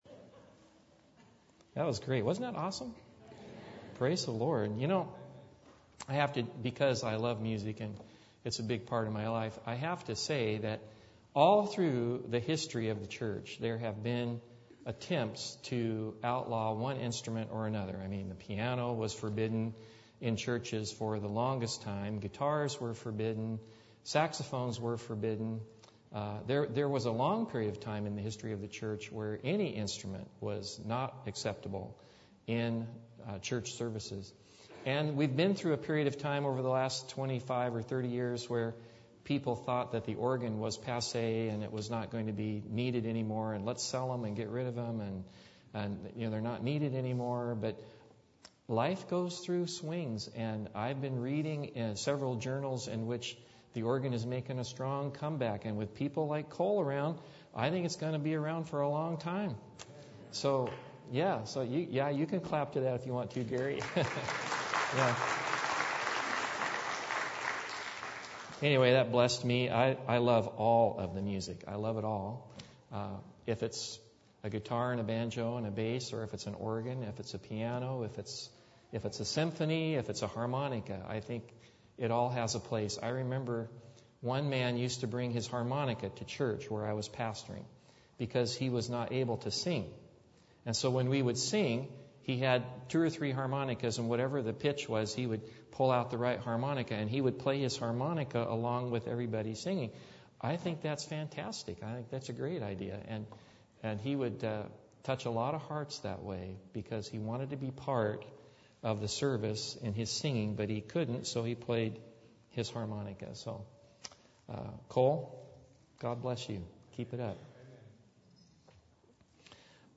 Service Type: Sabbath